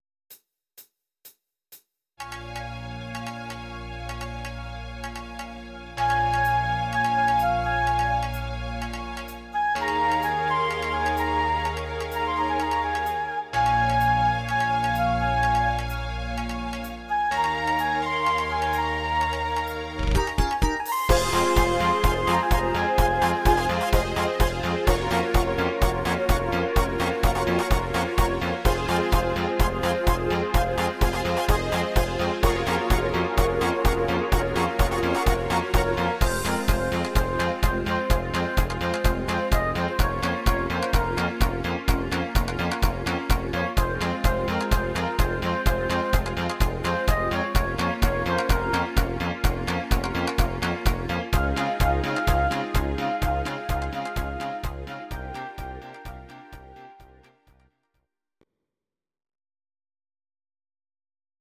Audio Recordings based on Midi-files
Pop, Disco, 1980s